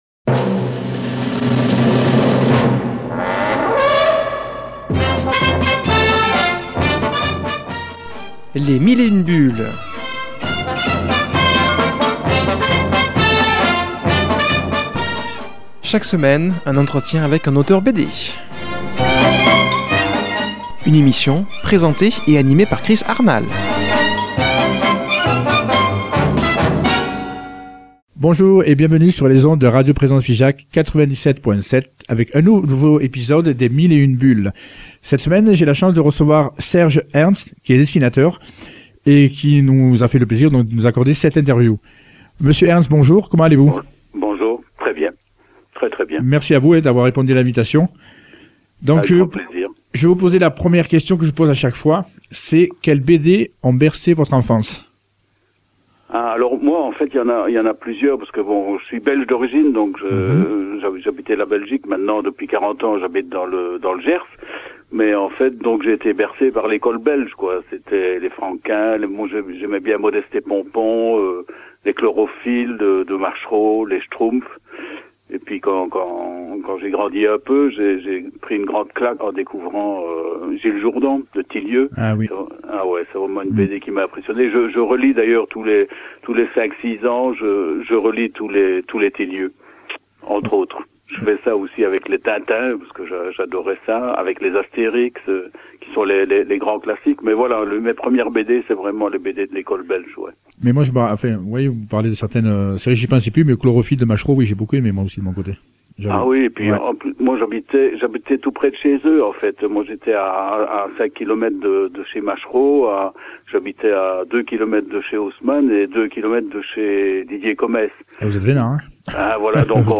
qui a comme invitée au téléphone le dessinateur